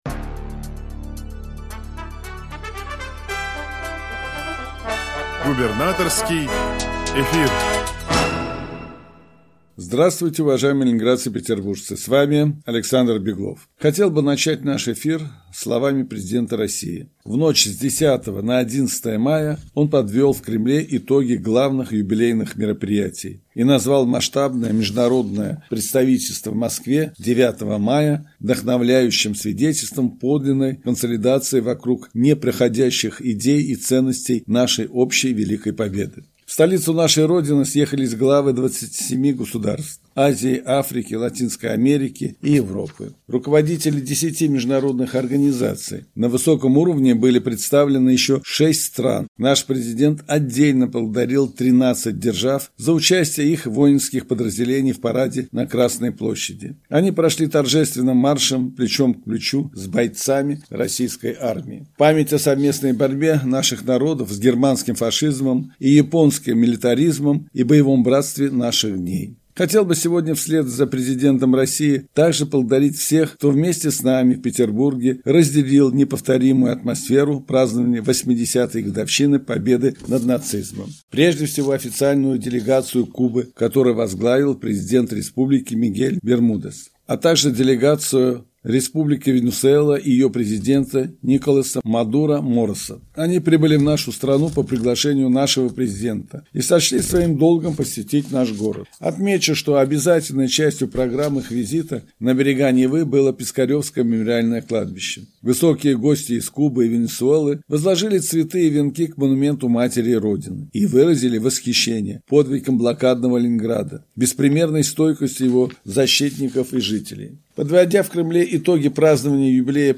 Радиообращение – 12 мая 2025 года